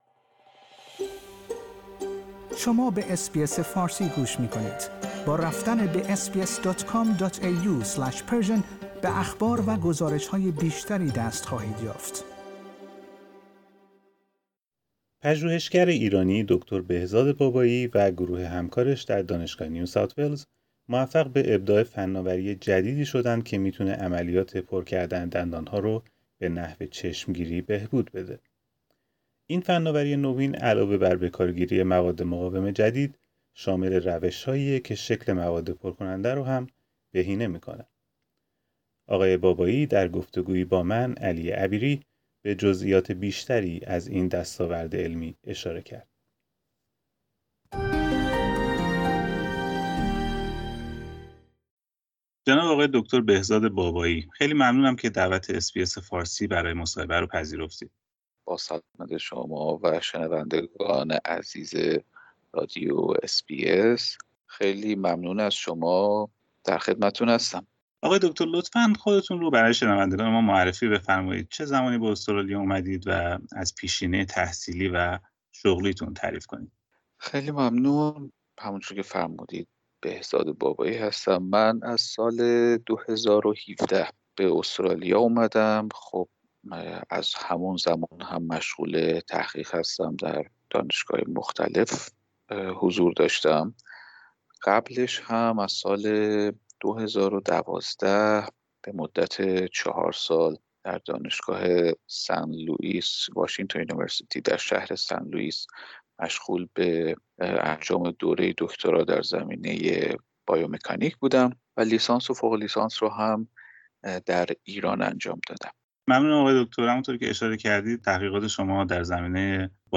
در گفتگویی با اس بی اس فارسی به جزییات بیشتری از این دستاورد علمی اشاره کرد.